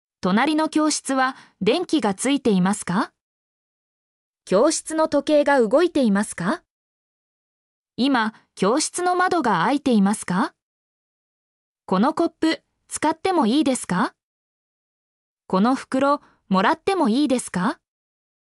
mp3-output-ttsfreedotcom-21_yy2LwdNg.mp3